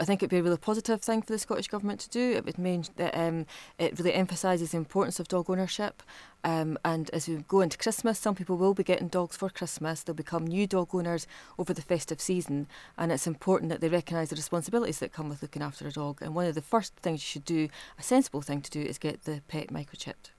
She's telling us it's especially important at this time of year: